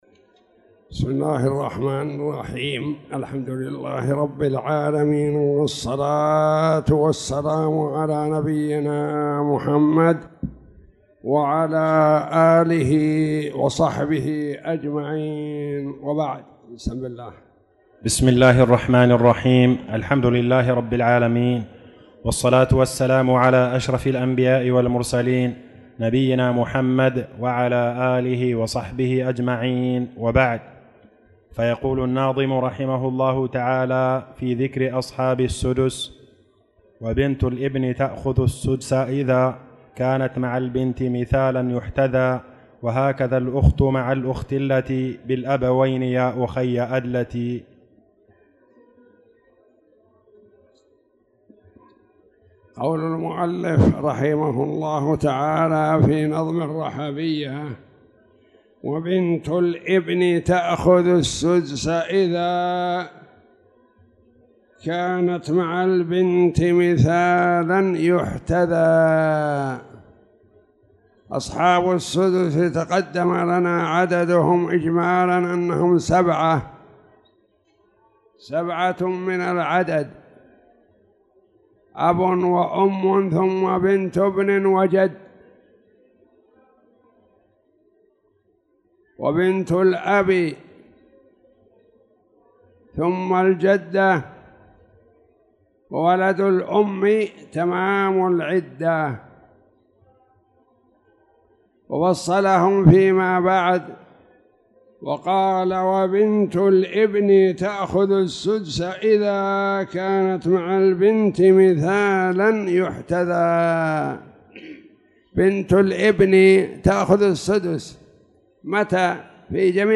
تاريخ النشر ٣ شعبان ١٤٣٧ هـ المكان: المسجد الحرام الشيخ